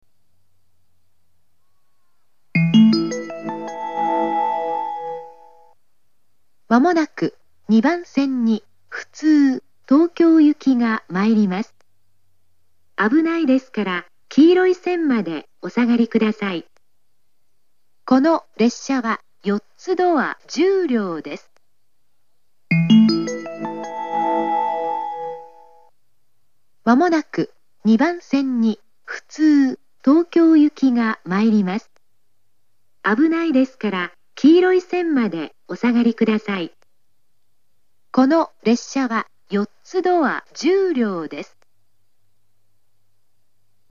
２番線接近放送
nebukawa2bansen-sekkin.mp3